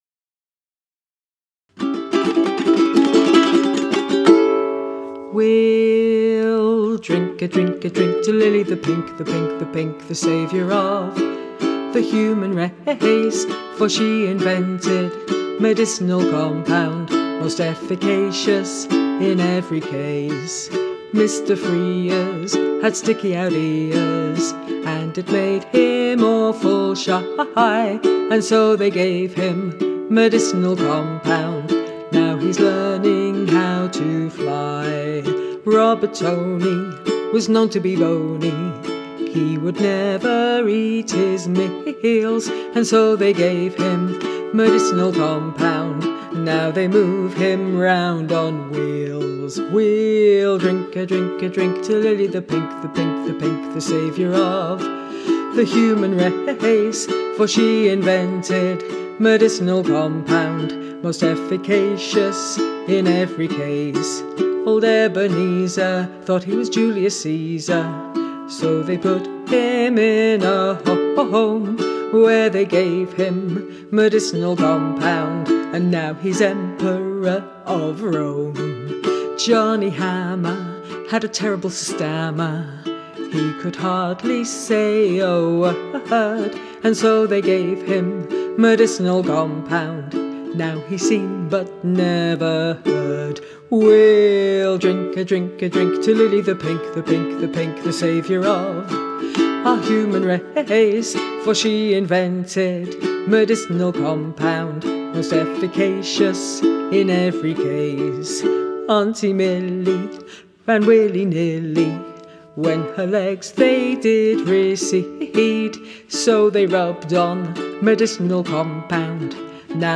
A comedy Christmas number one from 1968
bass
backing vocals